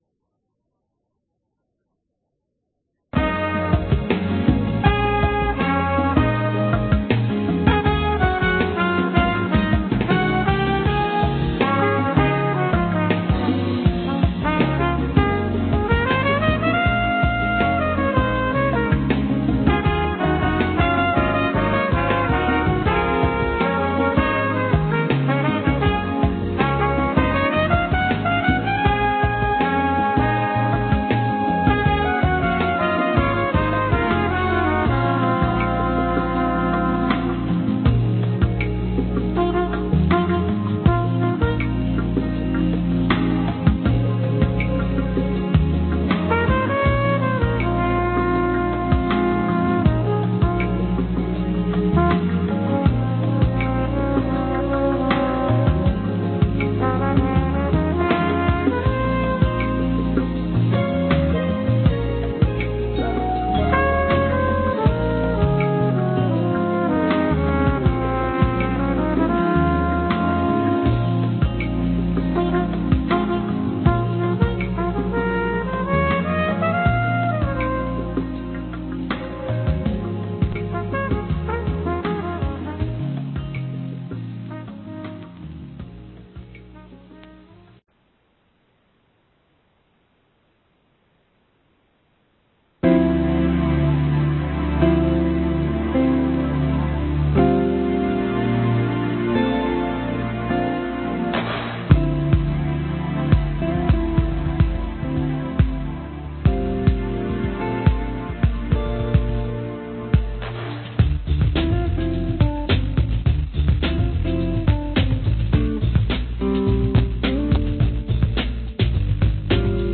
ICN-WEN Webinar